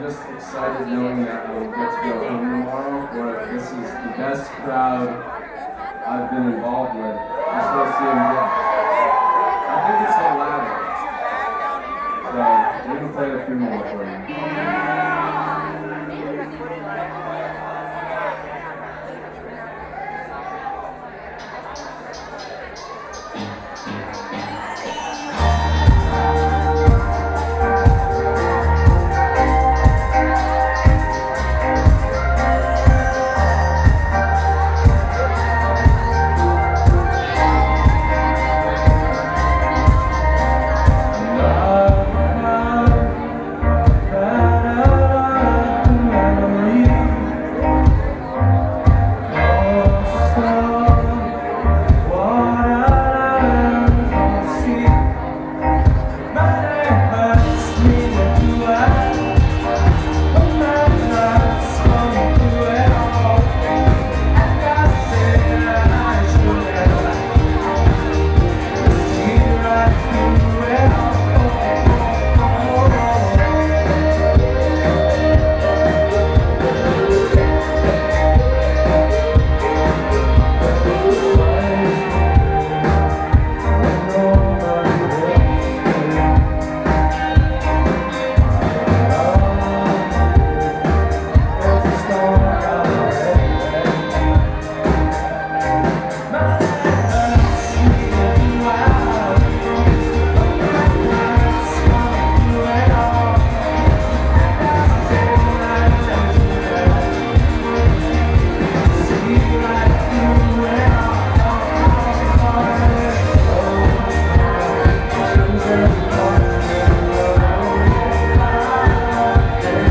CMJ 2010 Report Part 2 of 10.
Below is a live track from CMJ.